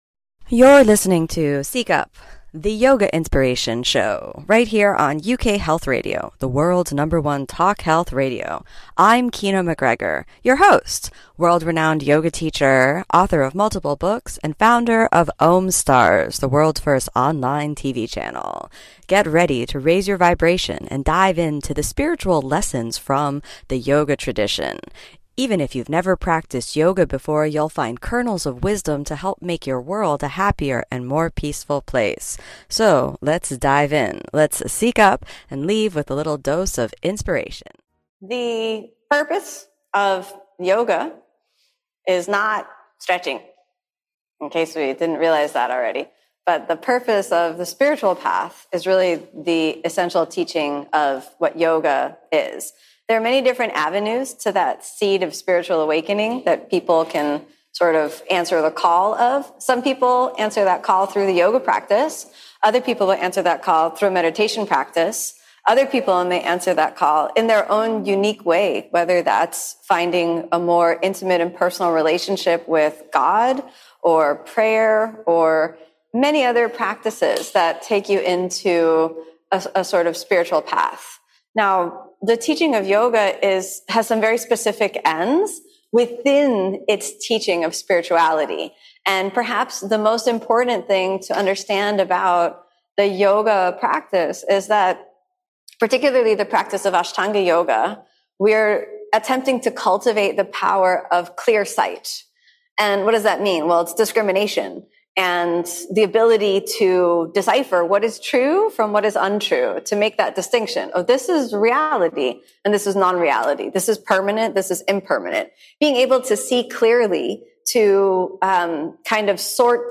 Listen to authentic, raw conversations and talks from Kino on her own and with real students about what yoga is really all about.